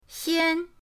xian1.mp3